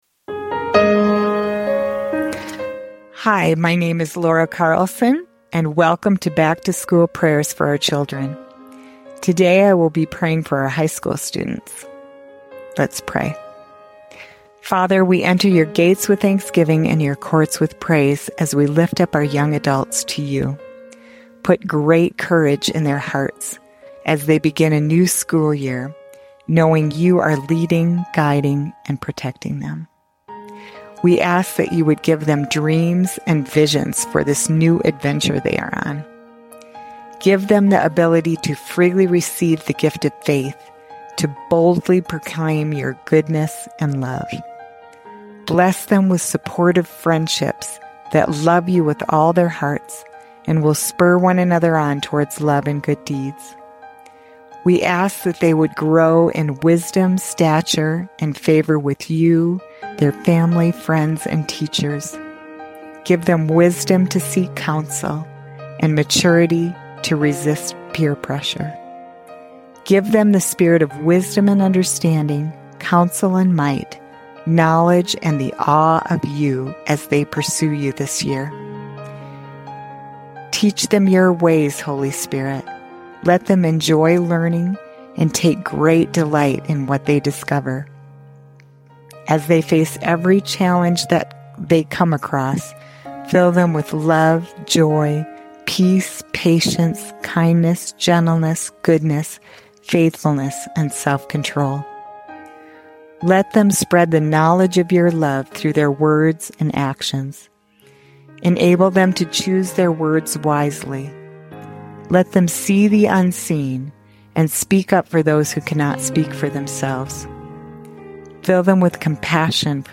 In this heartfelt time of prayer
She also prays a blessing over mamas, reminding us that we can entrust our children to the loving care of our Heavenly Father.